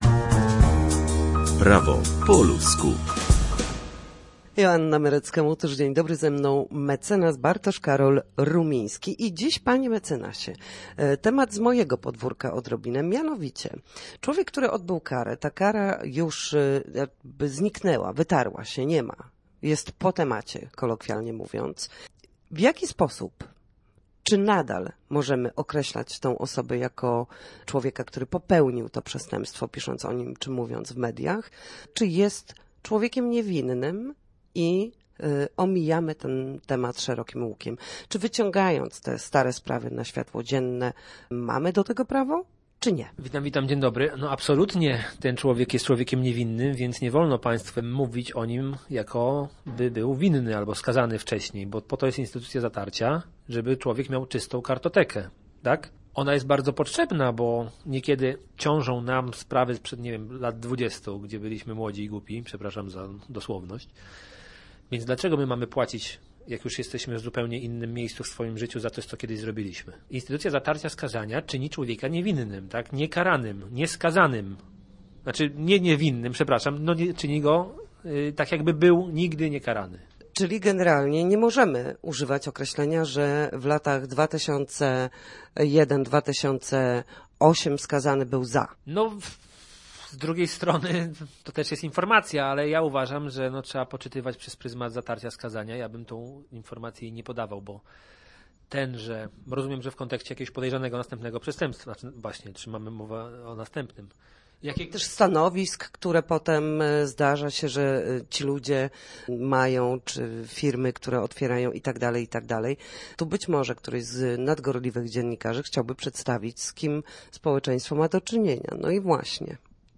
W każdy wtorek o godzinie 13:40 na antenie Studia Słupsk przybliżamy państwu meandry prawa. Nasi goście, prawnicy, odpowiadać będą na jedno pytanie dotyczące zachowania w sądzie czy podstawowych zagadnień prawniczych.